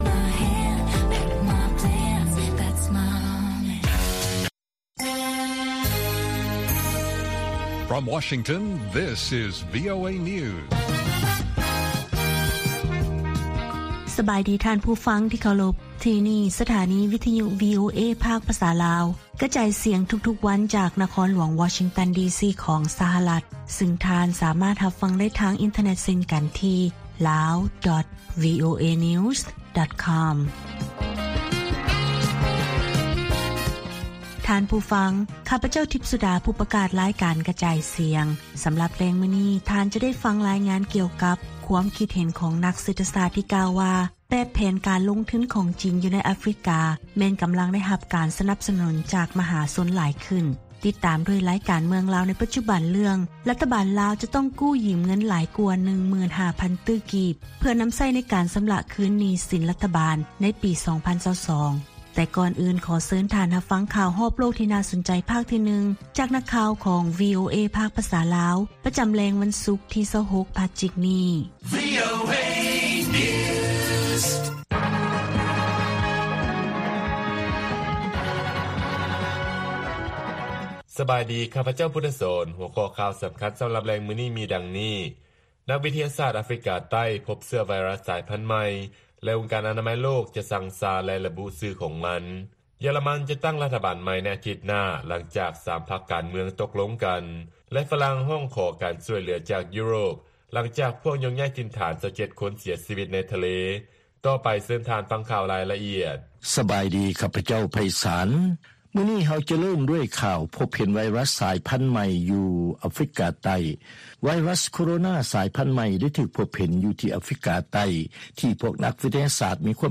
ລາຍການກະຈາຍສຽງຂອງວີໂອເອ ລາວ: ນັກວິທະຍາສາດອາຟຣິກາໃຕ້ ພົບເຊື້ອໄວຣັສສາຍພັນໃໝ່ ແລະອົງການອະນາໄມໂລກ ຈະຊັ່ງຊາ ແລະລະບຸຊື່ຂອງມັນ